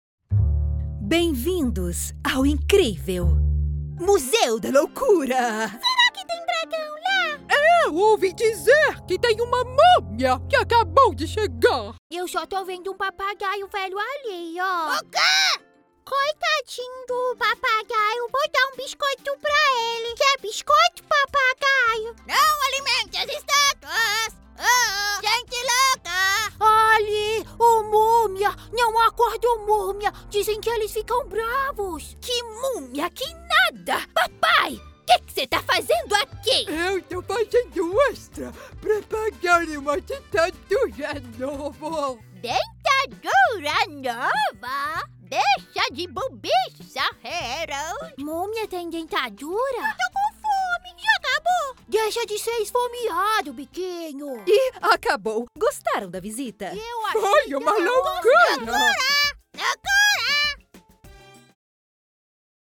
Animation
My vocal range spans ages 13 to 40.
HighMezzo-Soprano